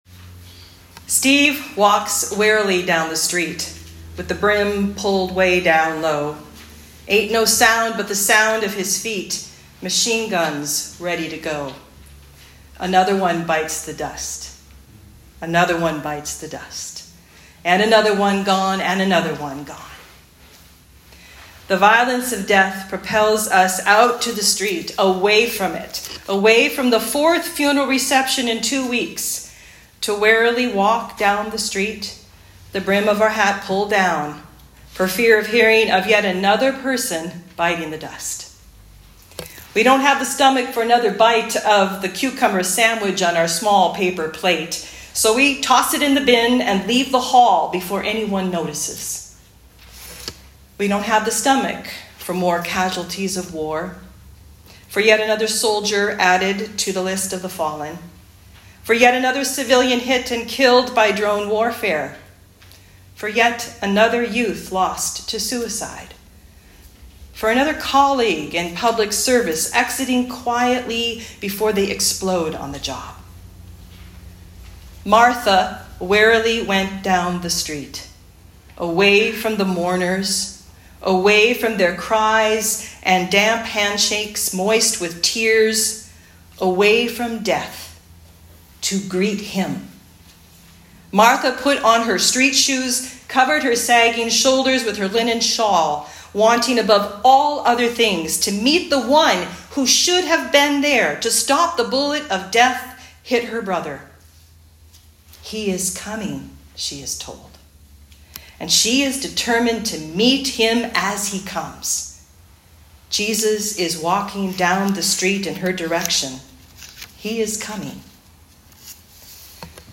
Remembrance Sunday Talk